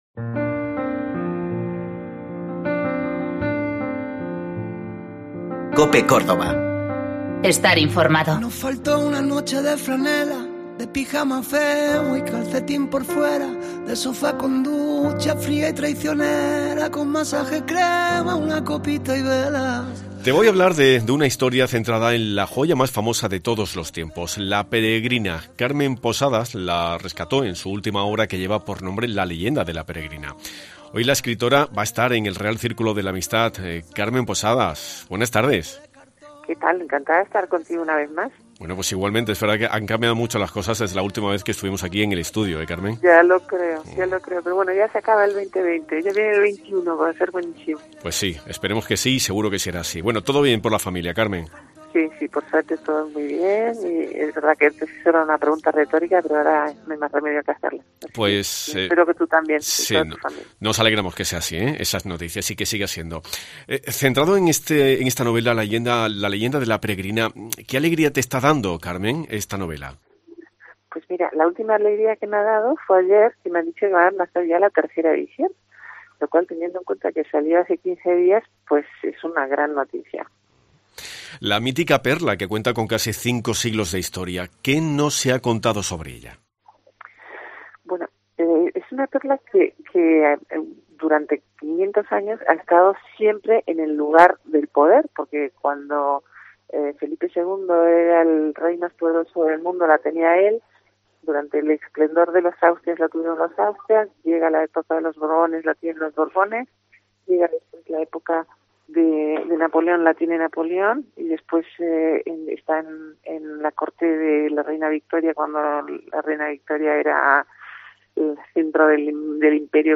Carmen Posadas, en la entrevista emitida en Mediodía COPE, ha desvelado que ayer recibió una noticia que la ha llenado de felicidad.